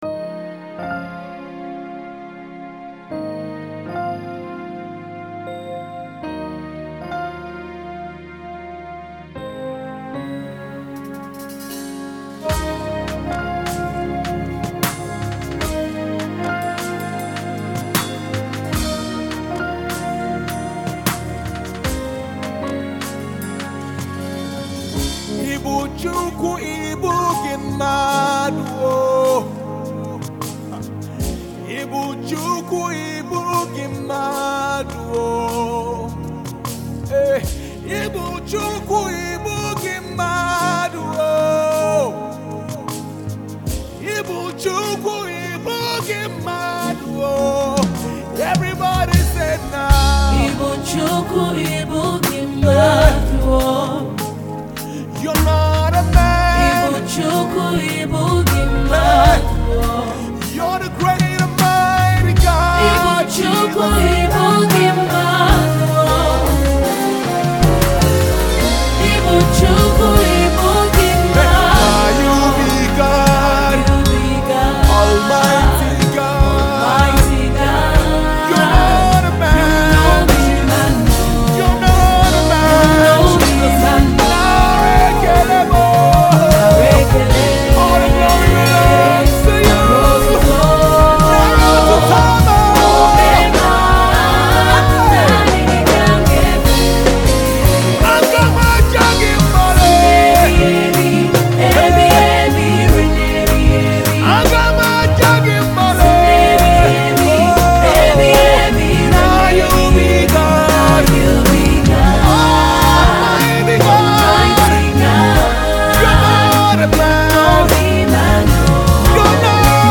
gospel songs
powerful worship song